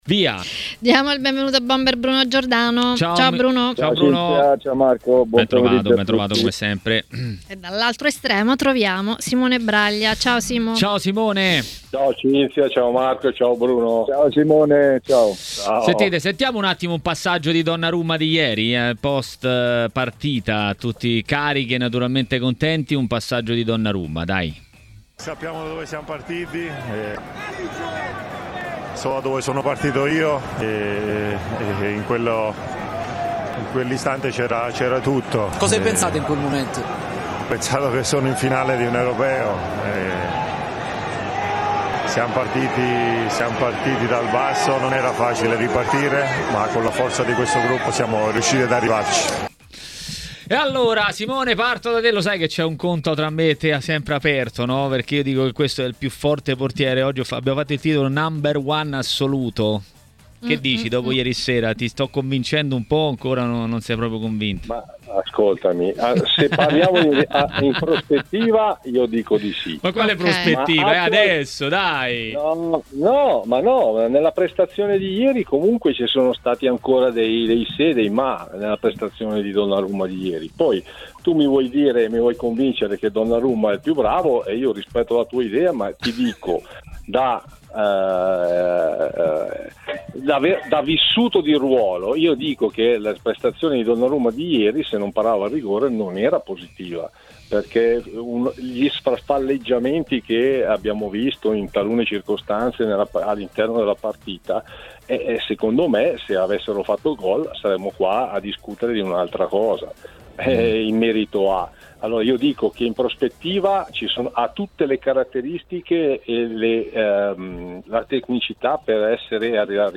A TMW Radio, durante Maracanà, è intervenuto per dire la sua sull'Italia a Euro 2020 l'ex portiere Simone Braglia.